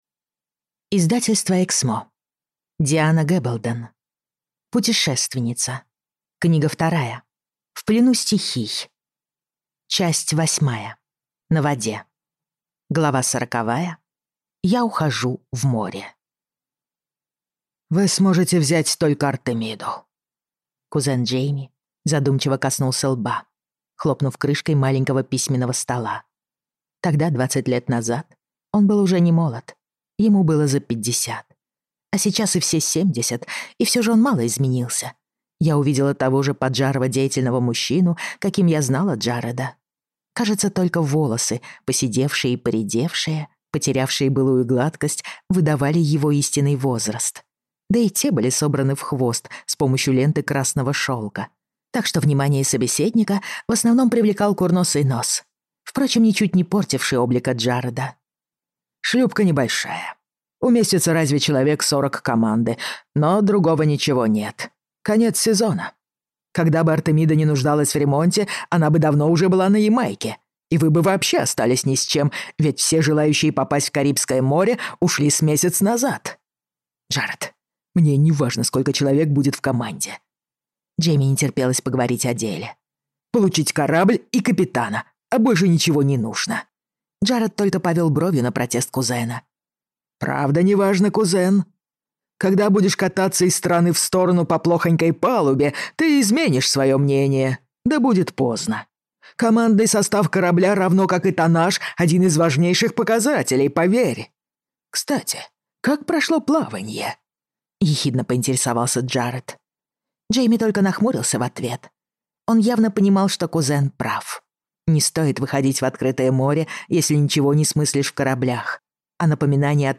Аудиокнига Путешественница. Книга 2. В плену стихий | Библиотека аудиокниг